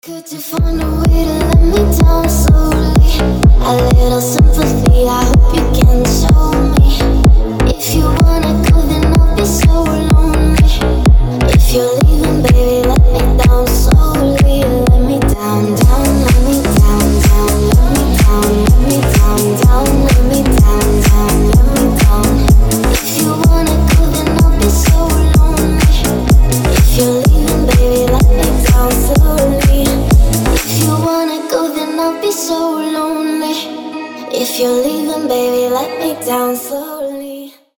• Качество: 320, Stereo
EDM
красивый женский голос
ремиксы
slap house